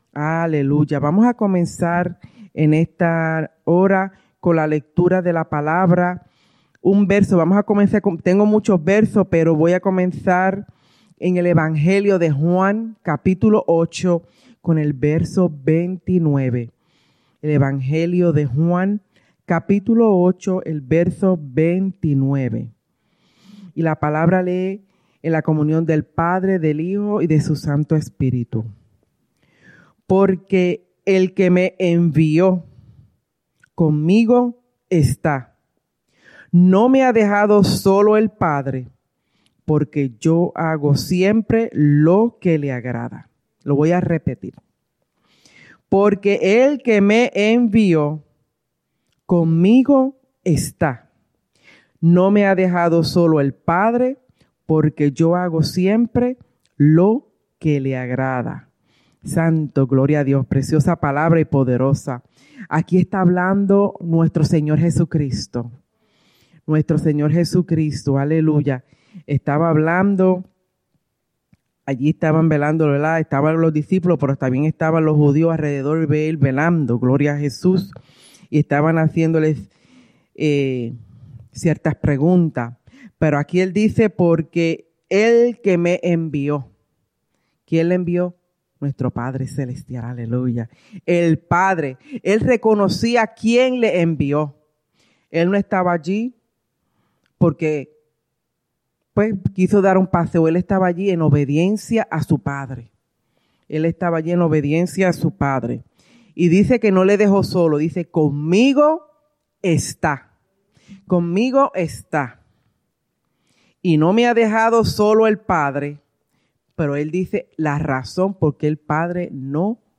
@ Souderton, PA